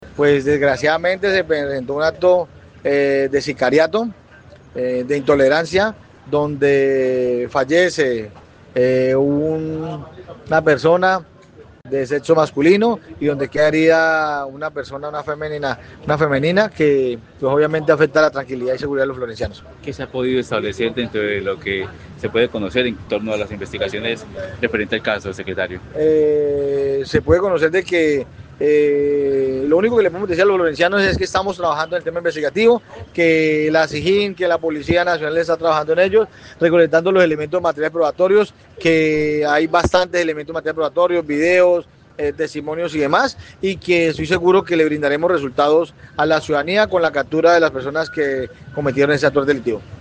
Carlos Mora, secretario de gobierno y seguridad ciudadana, explicó que el hecho enciende las alarmas, y obliga a las autoridades a adoptar medidas más drásticas en materia de prevención a la hora de evitar la ocurrencia de este tipo de hechos.